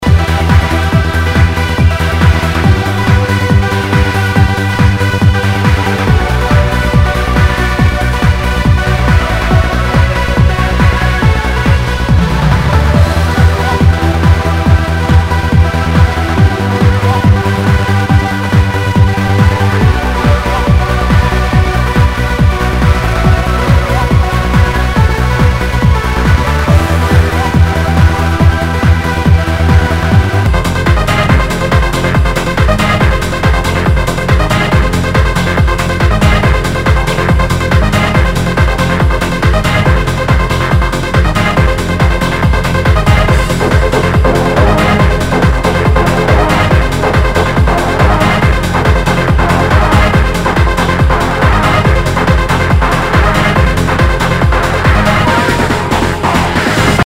HOUSE/TECHNO/ELECTRO
ナイス！トランス！
全体にチリノイズが入ります